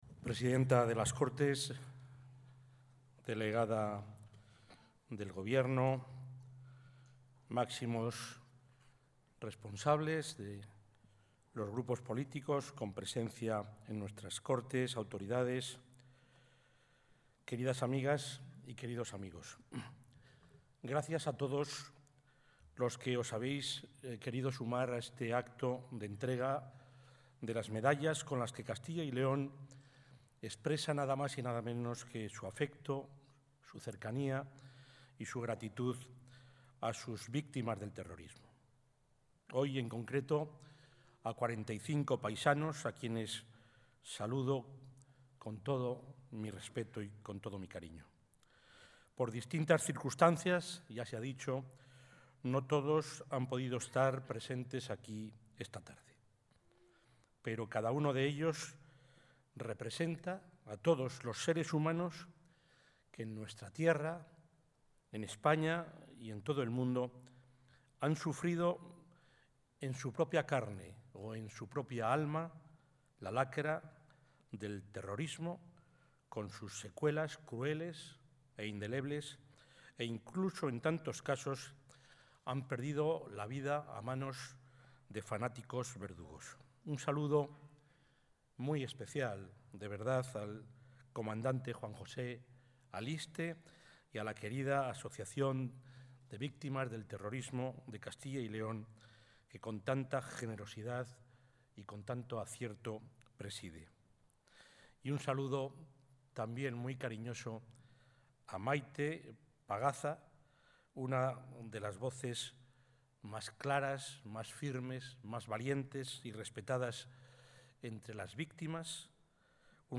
El acto celebrado esta tarde en el Centro Cultural Miguel Delibes de Valladolid ha servido de homenaje al colectivo de las víctimas del...
Audio presidente.